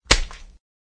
bulletExplode.ogg